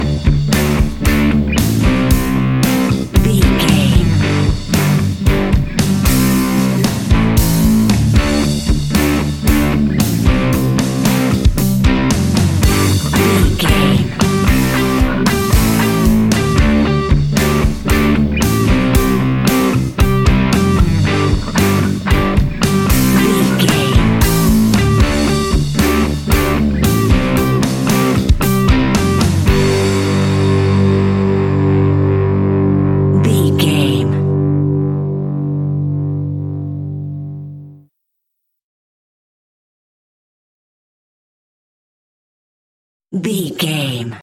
Epic / Action
Fast paced
Ionian/Major
hard rock
blues rock
instrumentals
Rock Bass
heavy drums
distorted guitars
hammond organ